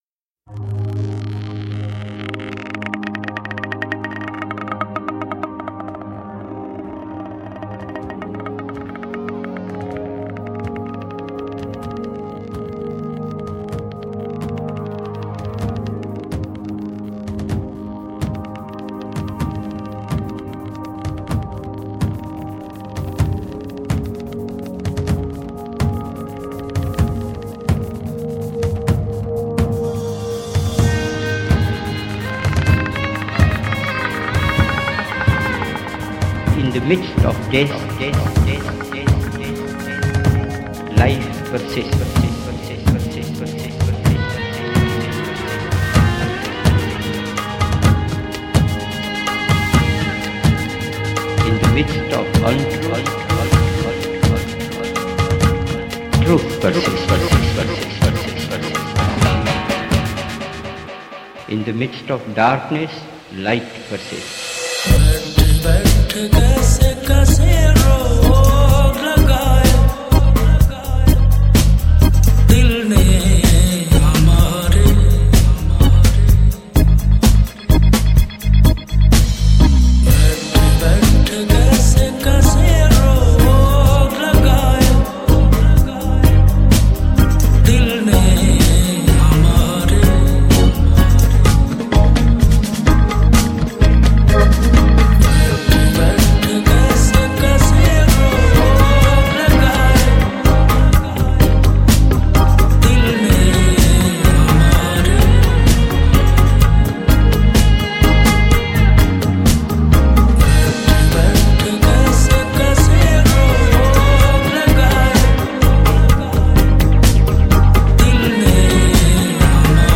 Sufi Collection